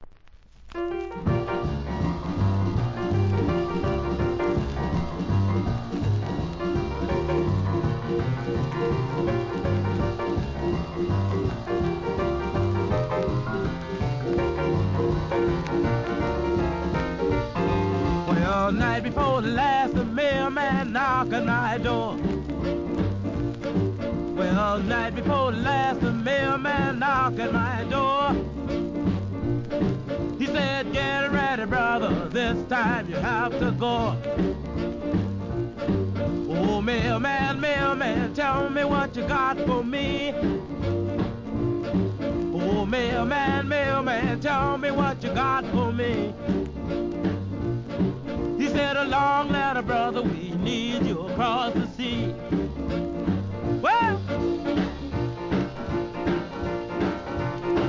Rhythm & Blues